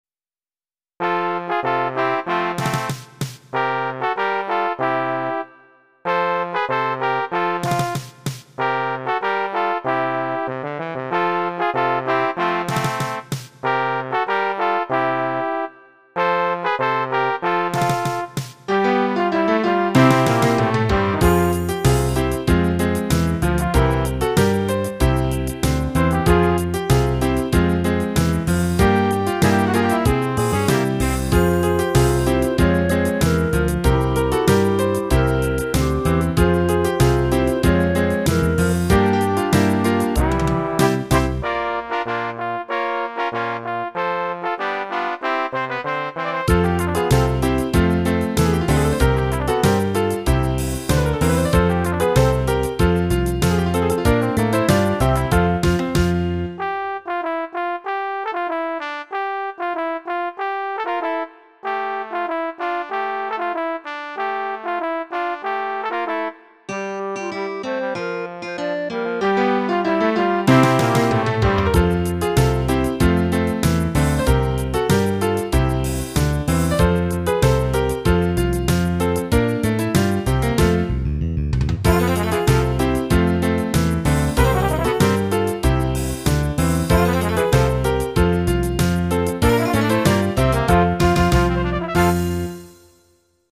This is from Jim Taylor's "Design" theme, and a MIDI version I did of it in February 2005. I wouldn't call it a true variation, but I did add a lot of parts and a couple new sections.